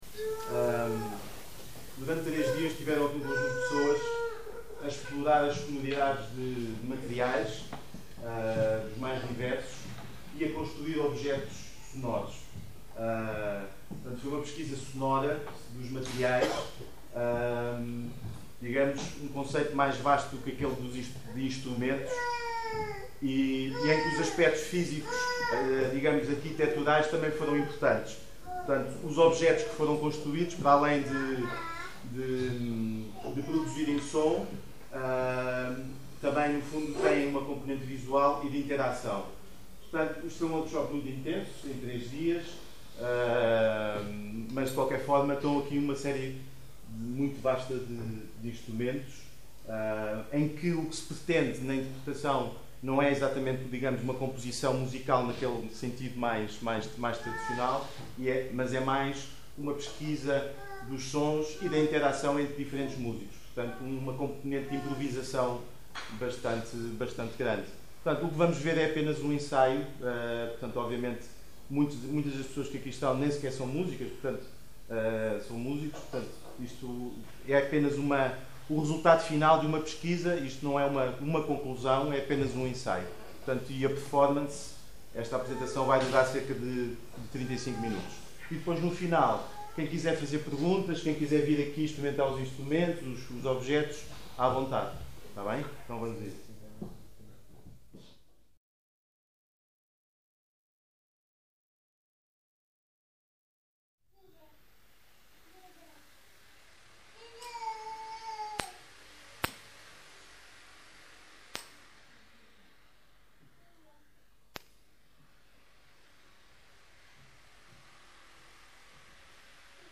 some photos from the workshop along with an audio file from the final composition
Apresentacao_Teatro_Viriato_Sound Objects.mp3